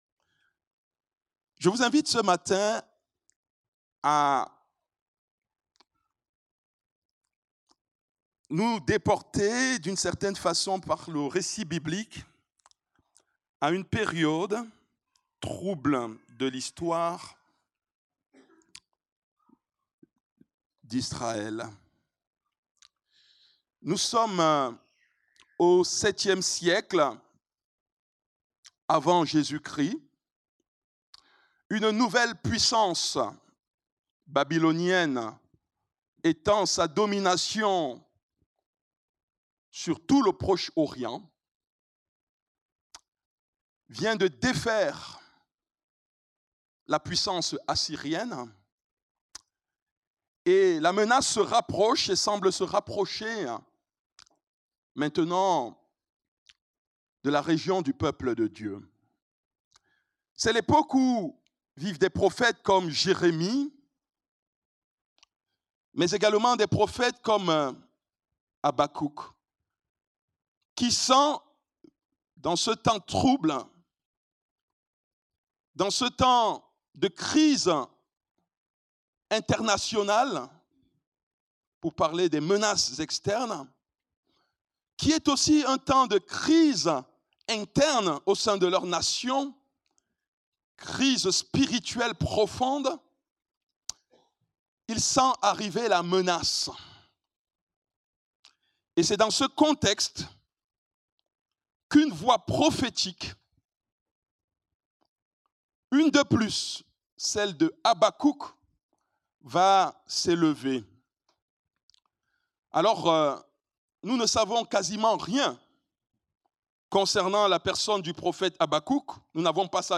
Culte du dimanche 24 Août 2025, prédication